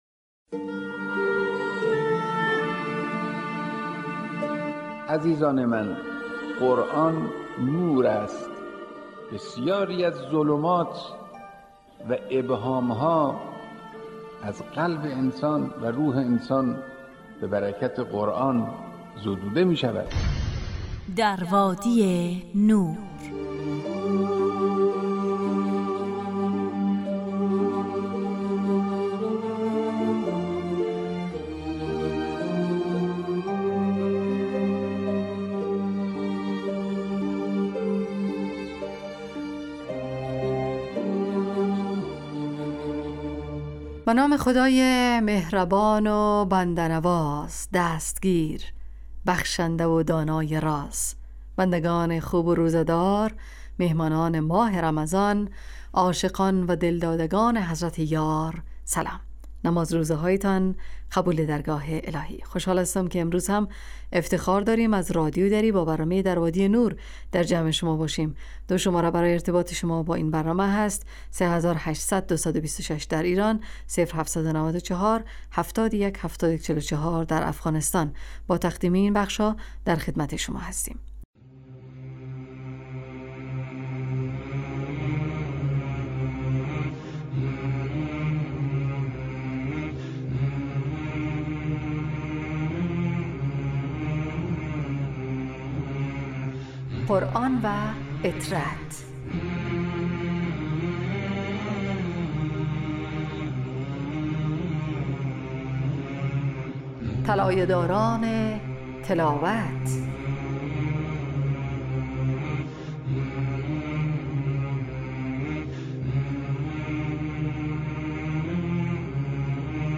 ایستگاه تلاوت